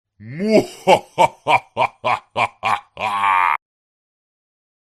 Download "Evil Laugh" — a free Horror sound effect.
Evil Laugh
049_evil_laugh.mp3